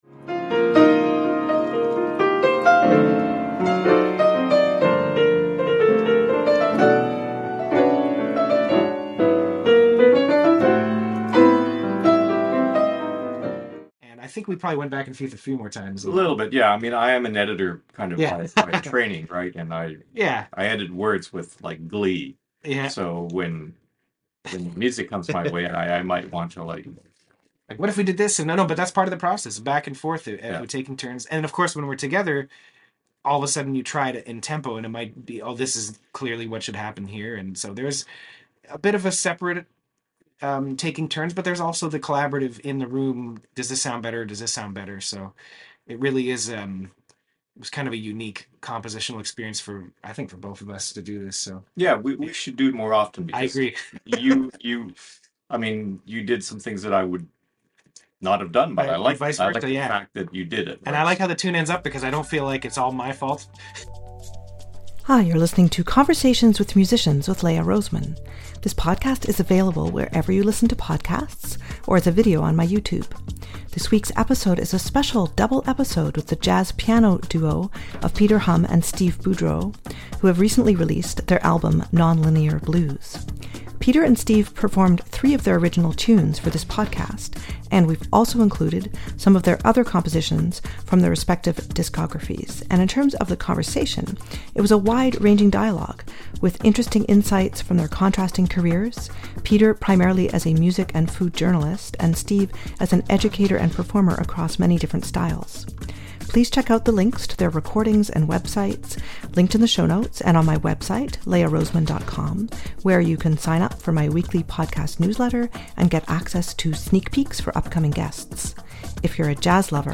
Jazz Piano Duo